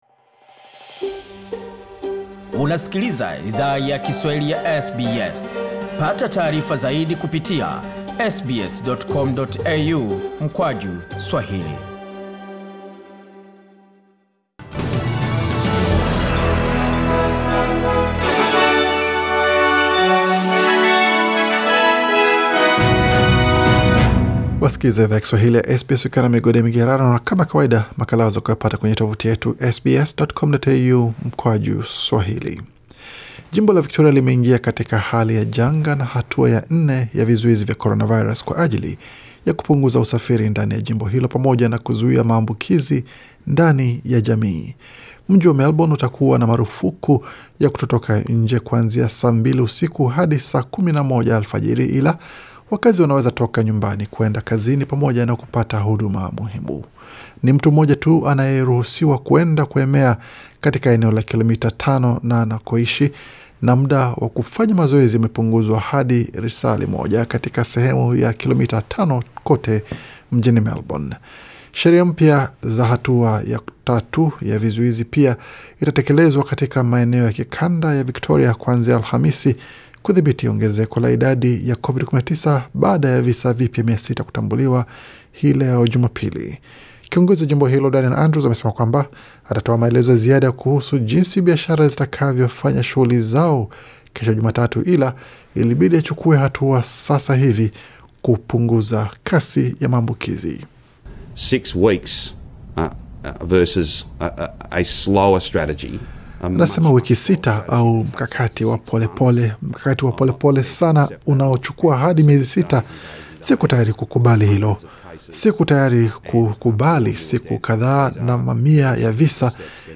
Taarifa ya habari 2 Agosti 2020